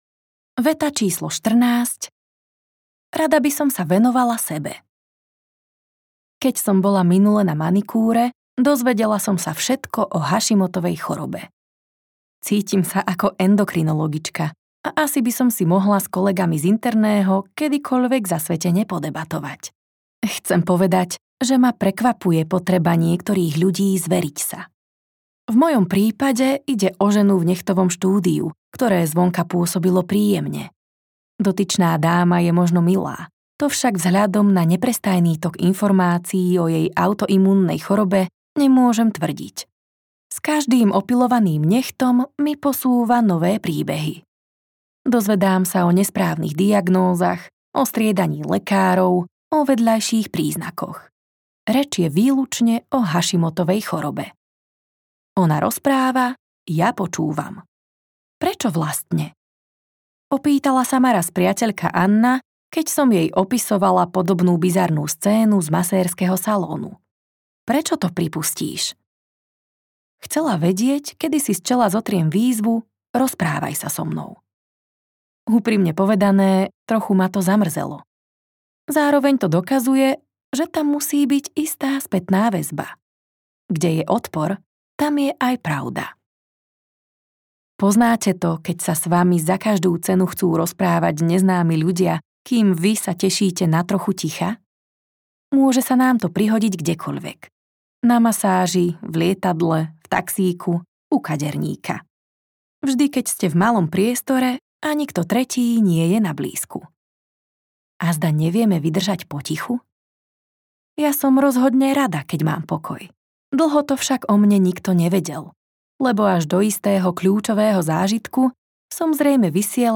Audiokniha 50 viet, ktoré vám uľahčia život - Karin Kuschik | ProgresGuru